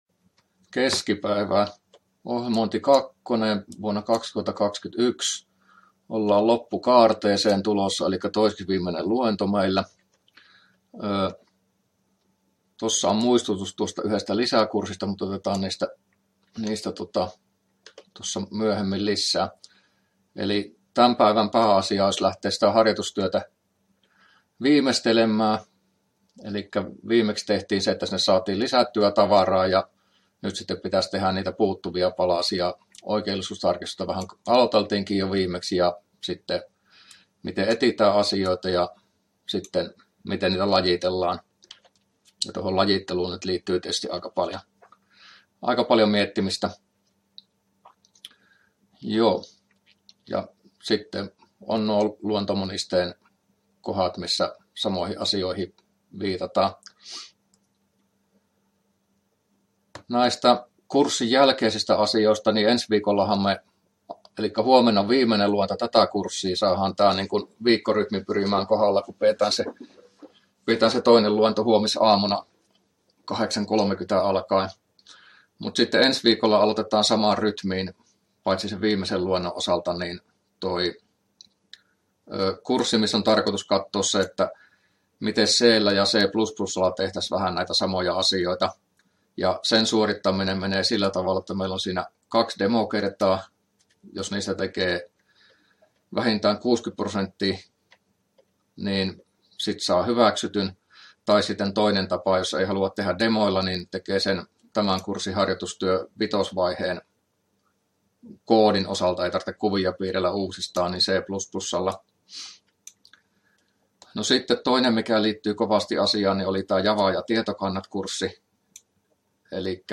luento23a